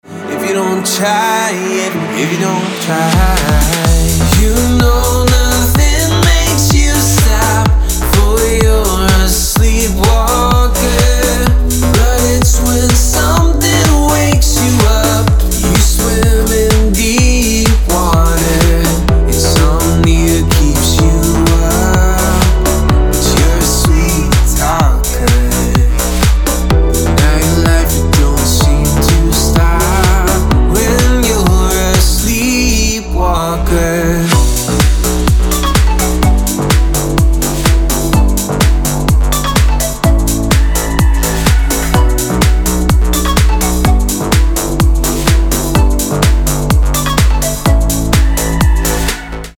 мужской вокал
dance
Electronic
house